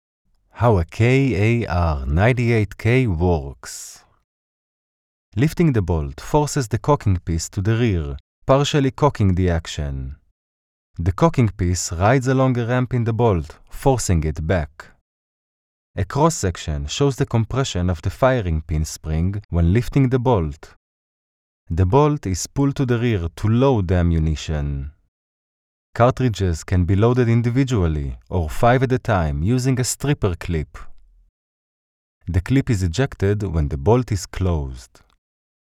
Experienced Hebrew native Male narrator voiceover, (hebräisch sprecher), with a pro home studio, deep ,warm voice, from Berlin.
Sprechprobe: Sonstiges (Muttersprache):